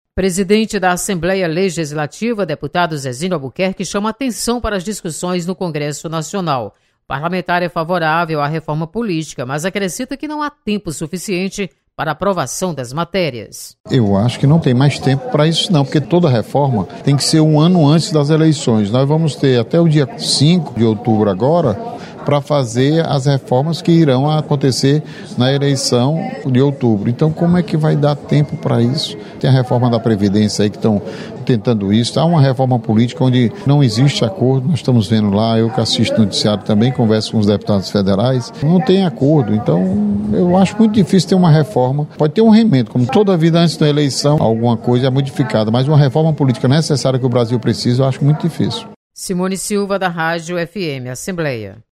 Deputado Zezinho Albuquerque alerta para importância de discussões sobre reforma política. Repórter